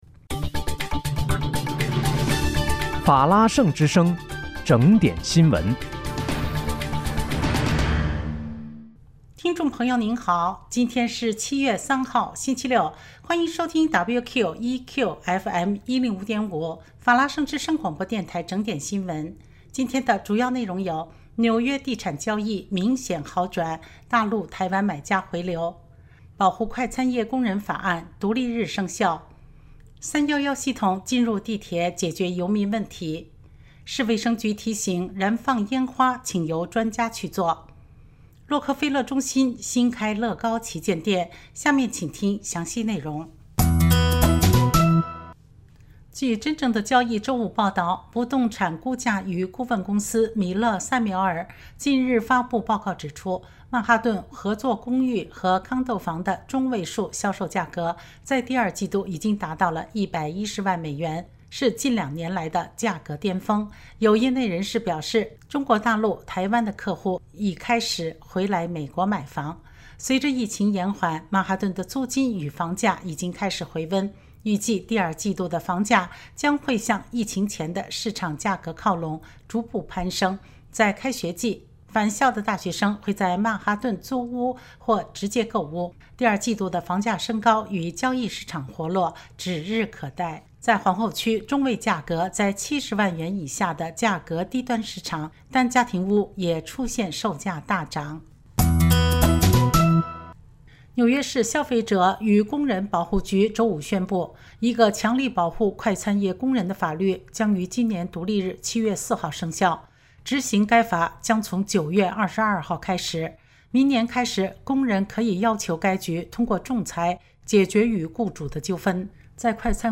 7月3日（星期六）纽约整点新闻